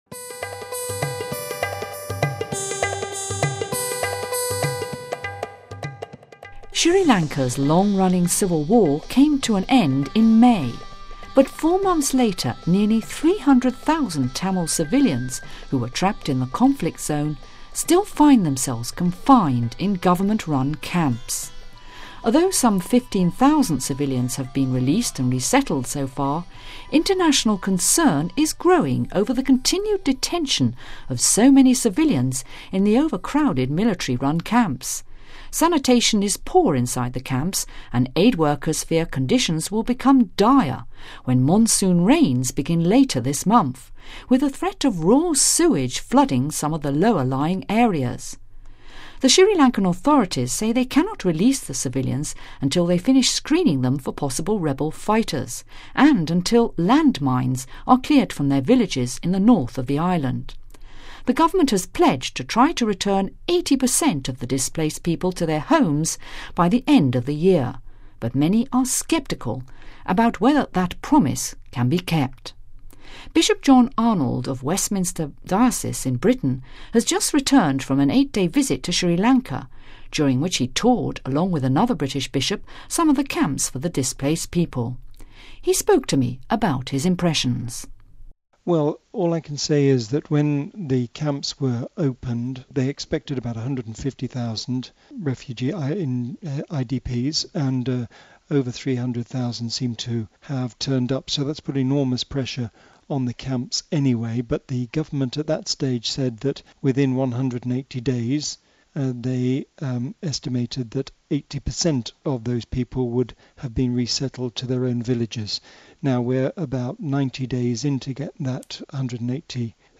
With international concern growing over the plight of nearly 300,000 Tamil civilians detained in grossly overcrowded government-run camps in Sri Lanka, we find out more about conditions inside from a British bishop who's just returned from a tour of the camps.....